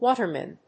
音節wá・ter・man 発音記号・読み方
/‐mən(米国英語), ˈwɔ:tɜ:mʌn(英国英語)/